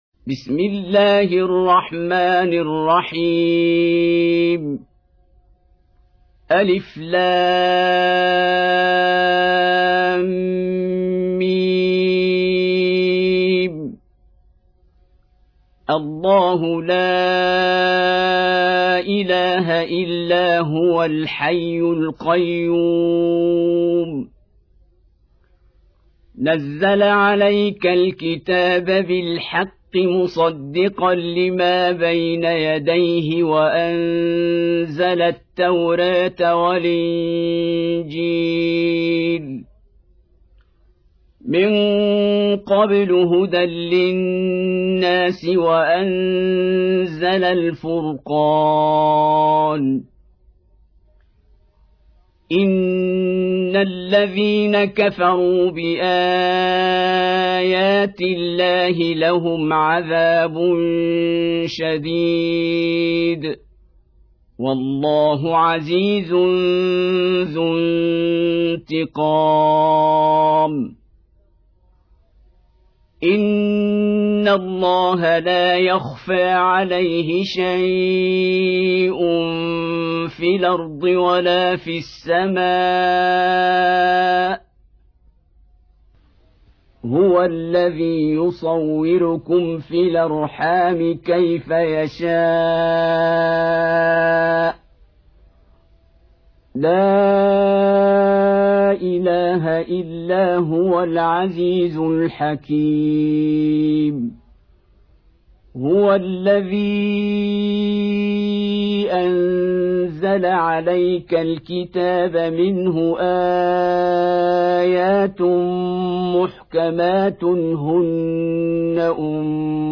Audio Quran Tarteel Recitation Home Of Sheikh Abdul-Basit Abdul-Samad
Surah Repeating تكرار السورة Download Surah حمّل السورة Reciting Murattalah Audio for 3. Surah �l-'Imr�n سورة آل عمران N.B *Surah Includes Al-Basmalah Reciters Sequents تتابع التلاوات Reciters Repeats تكرار التلاوات